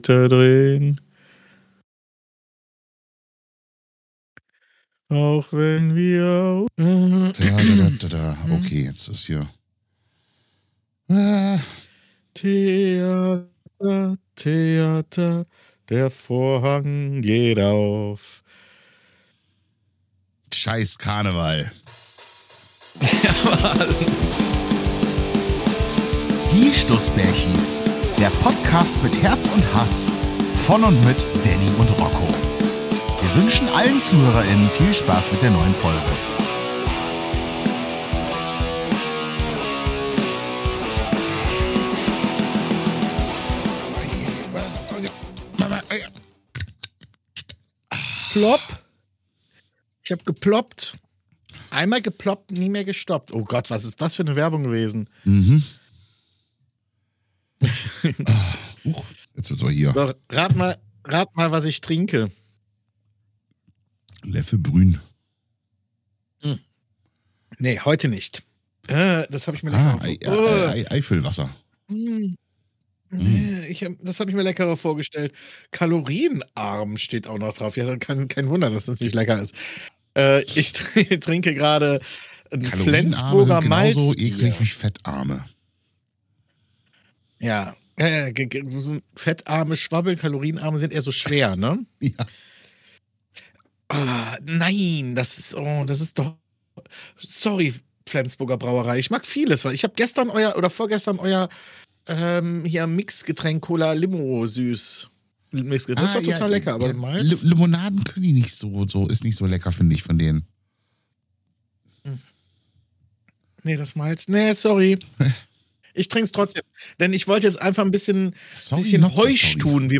Entschuldigt die Soundqualität, der Sonobus fuhr leider nicht und so haben wir via Discord aufgenommen...